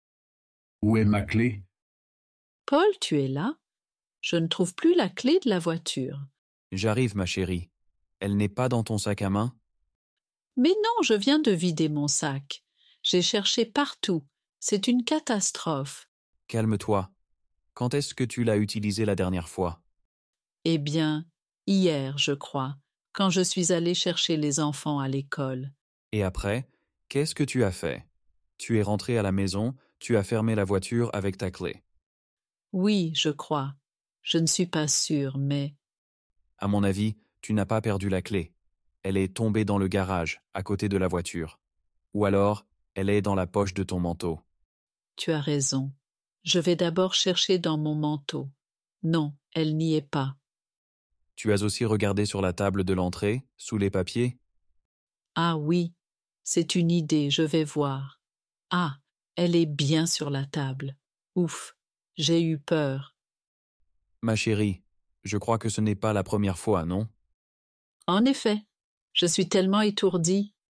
Dialogue en français – Où est ma clé ? (Niveau A2)